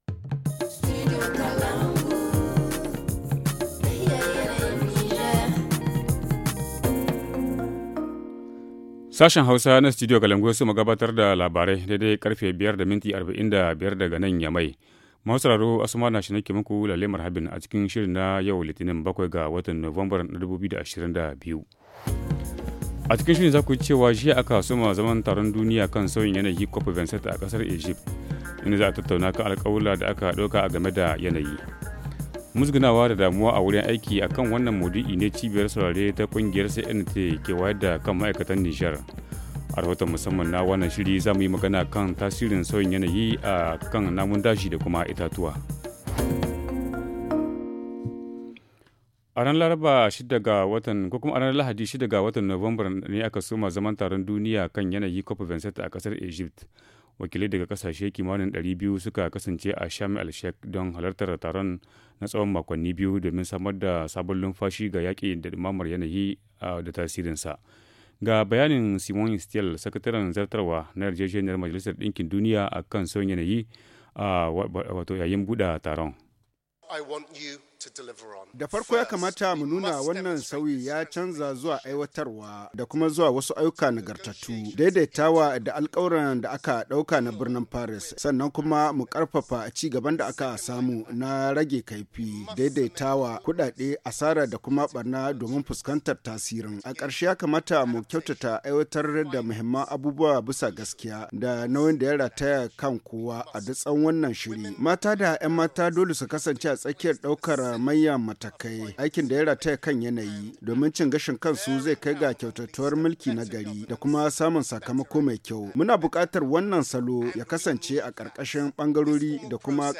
Le journal du 7 novembre 2022 - Studio Kalangou - Au rythme du Niger